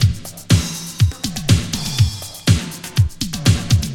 • 122 Bpm Classic Breakbeat C# Key.wav
Free drum loop sample - kick tuned to the C# note. Loudest frequency: 1512Hz
122-bpm-classic-breakbeat-c-sharp-key-p0C.wav